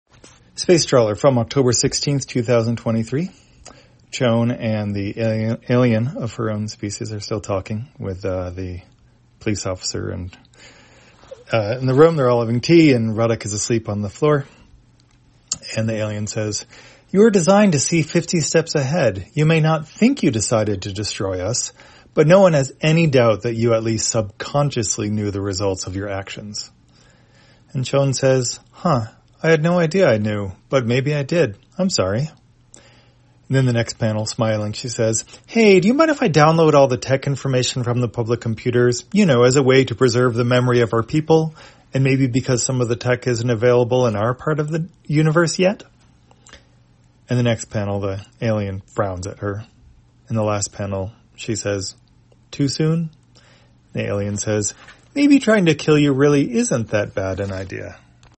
Spacetrawler, audio version For the blind or visually impaired, October 16, 2023.